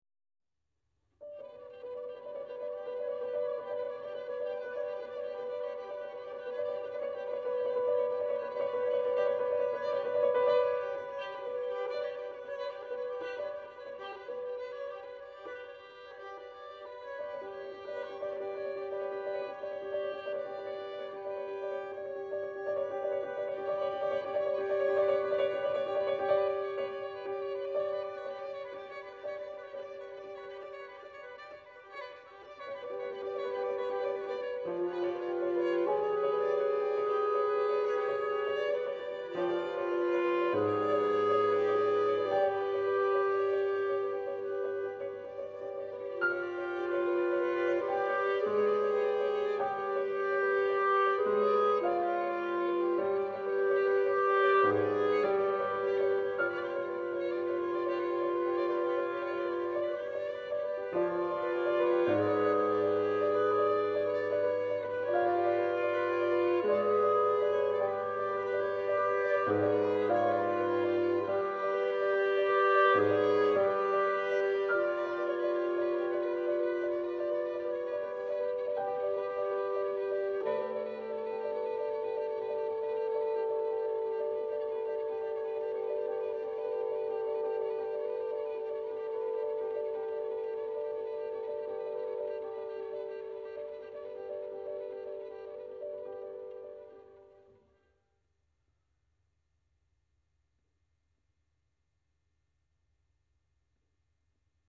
Genre chamber music
Instrumentation clarinet, violin, cello and piano
for clarinet in Bb, violin, cello and piano